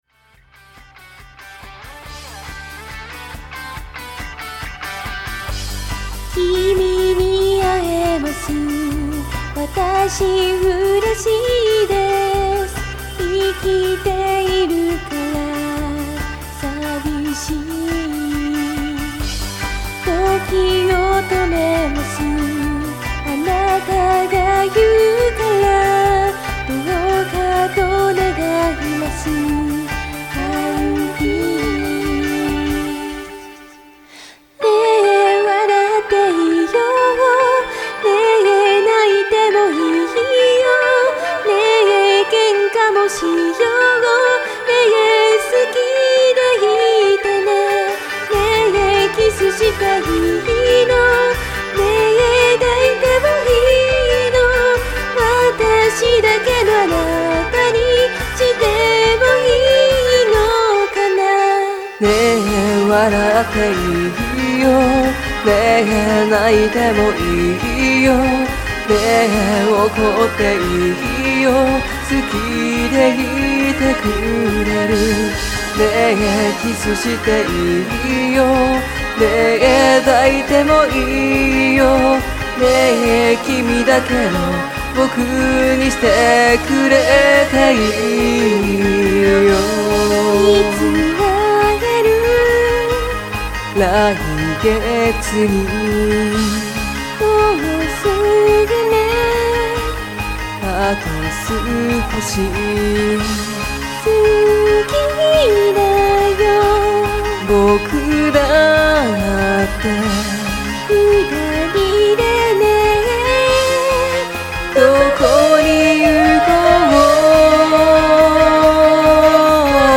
真剣に歌いすぎだろ…女の子視点だとこうなるのかなあと思って歌詞考えた。
喉が死ぬ。声気持ち悪くってすいませんorz